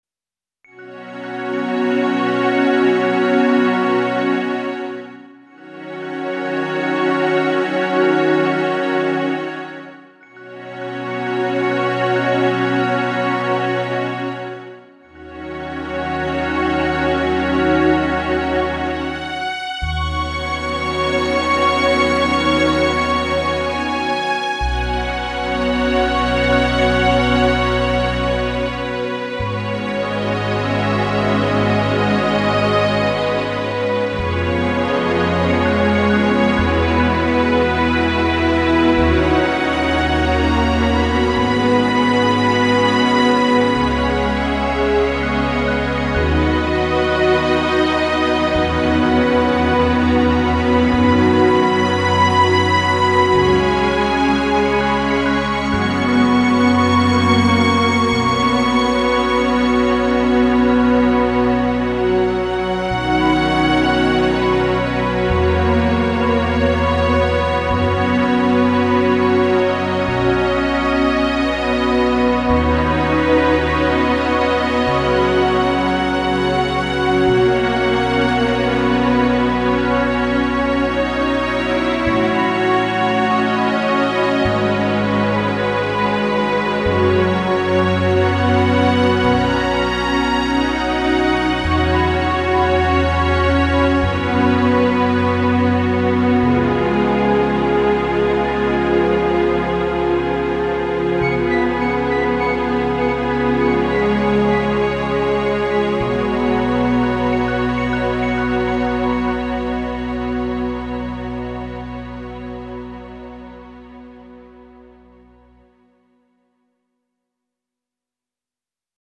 でも、まだ音が薄いかな？？